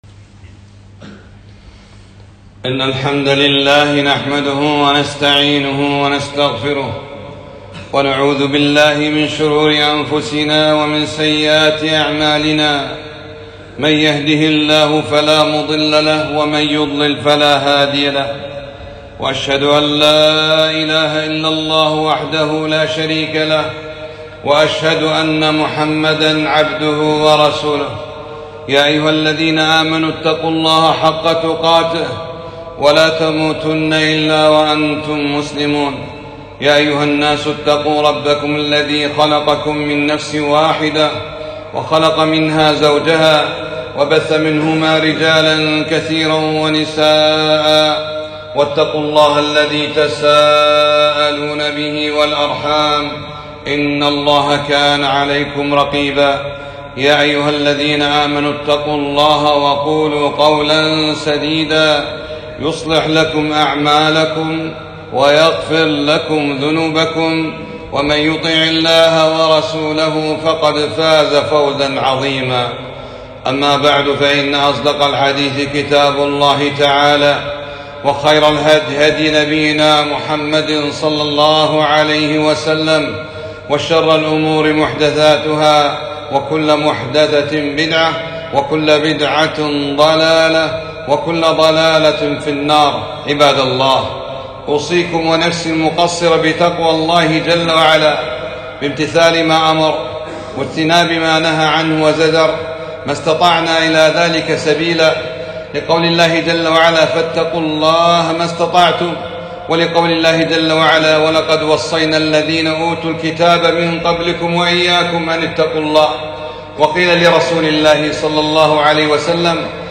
خطبة - لبُّ الصلاة وروحها